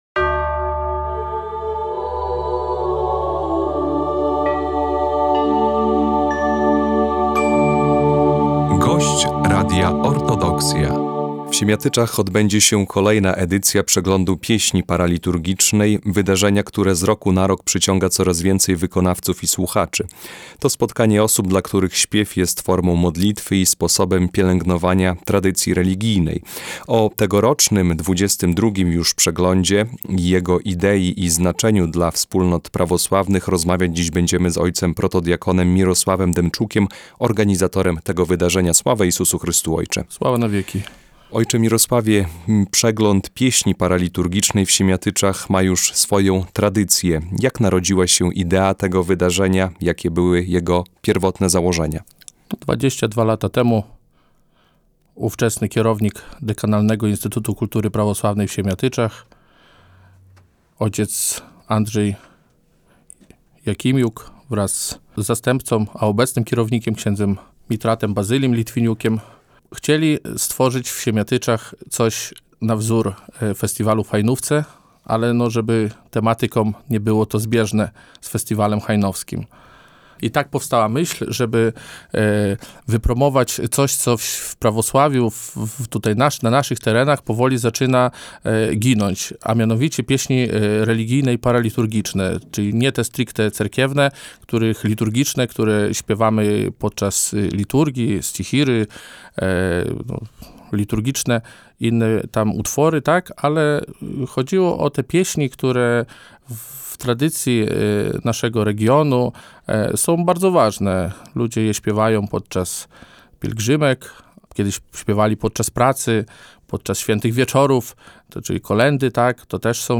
Nie tylko nuty – rozmowa o Międzynarodowym Przeglądzie Pieśni Religijnej i Paraliturgicznej w Siemiatyczach